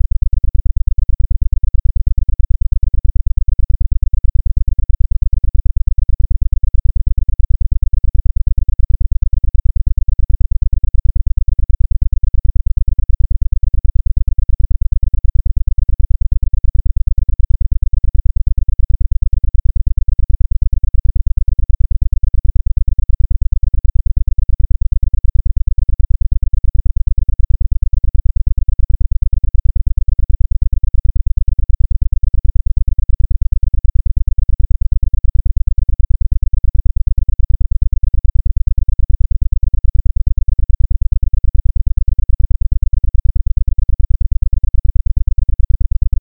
This is a trace wave of a 8 – 14 hz alpha lily wave of an mp3 file.
The above sound wave is based on the lily wave,
lily-wave-8-to-14-hz-web.mp3